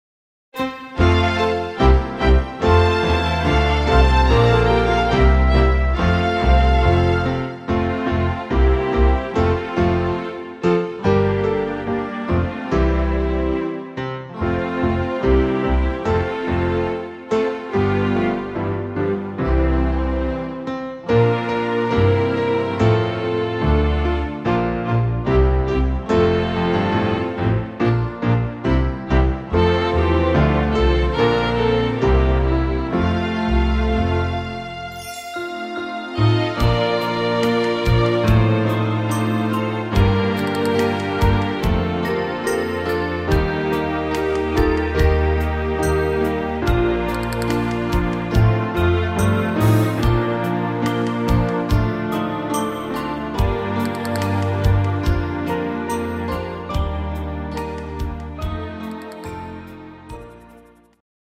modernes Kinderlied